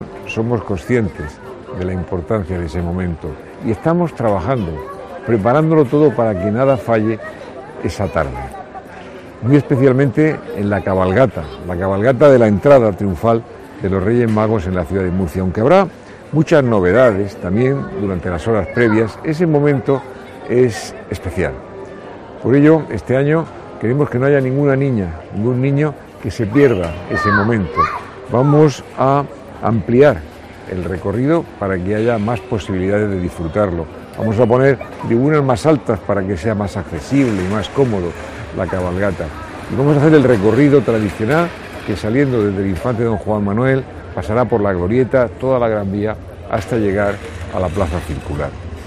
José Ballesta, alcalde de Murcia